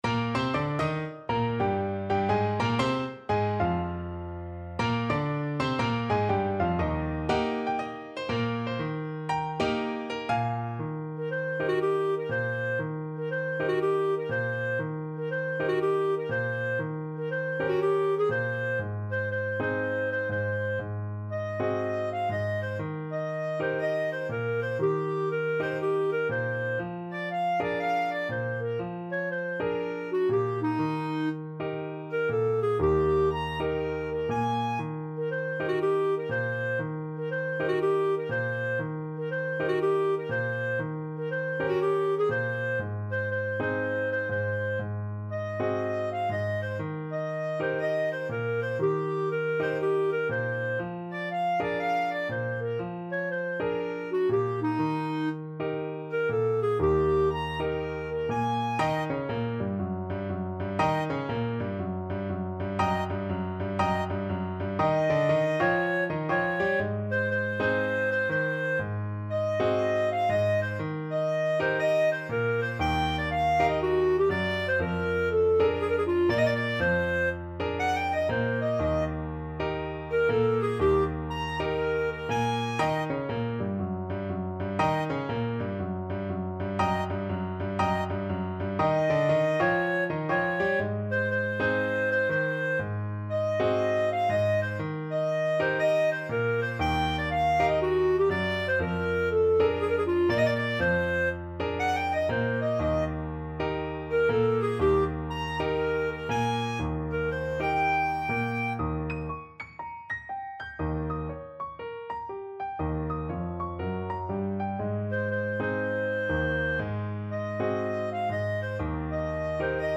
Moderato = 120
Jazz (View more Jazz Clarinet Music)